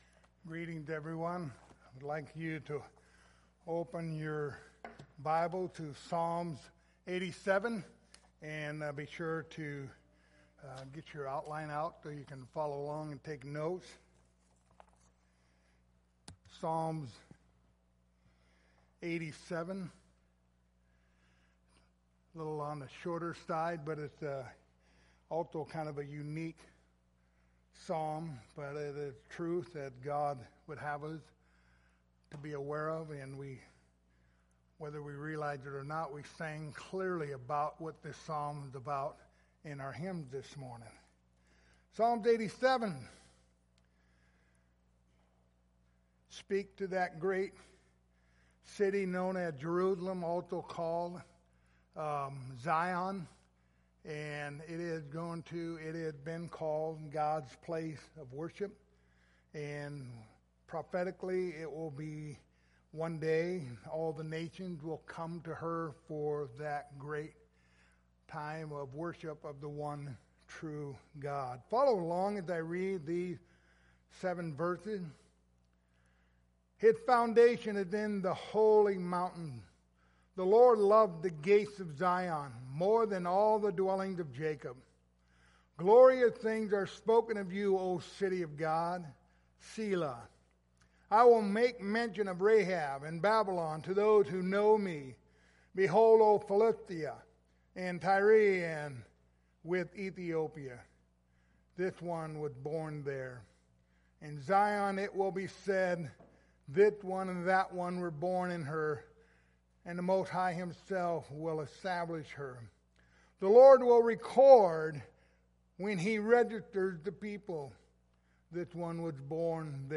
Passage: Psalms 87:1-7 Service Type: Sunday Morning